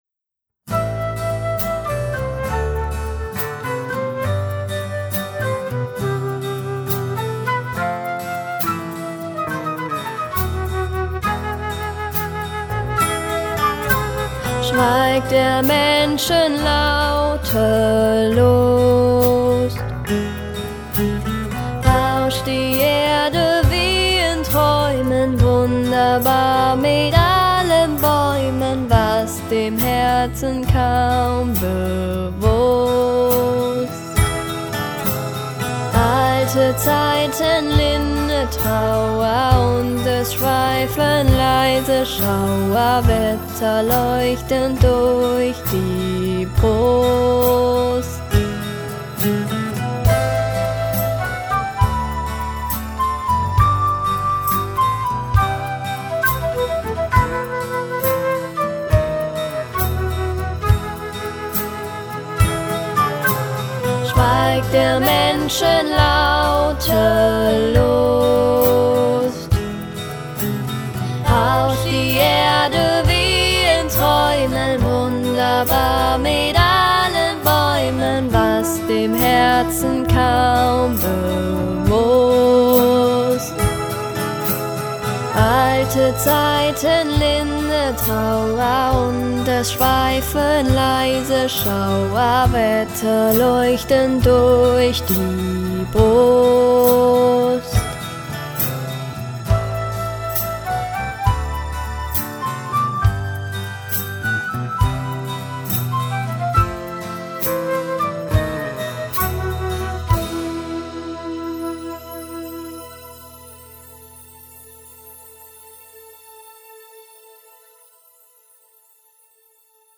Romantik pur!